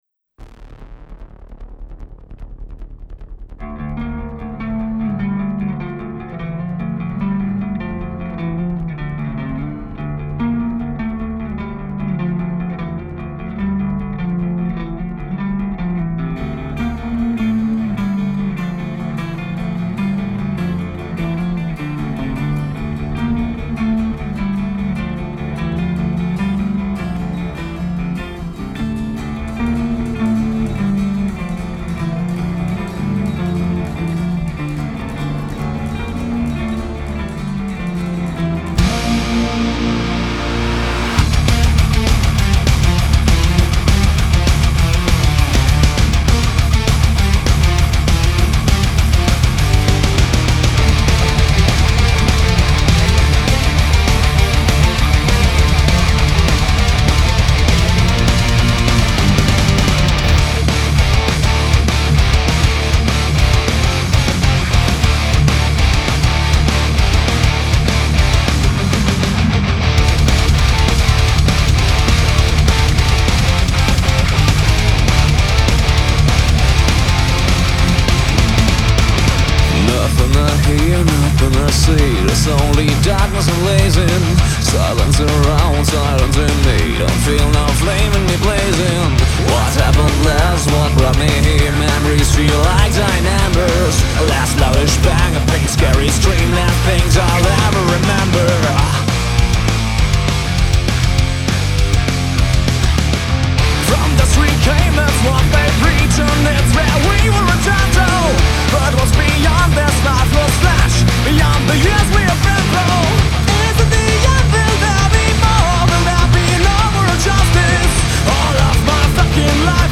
Melodic Metal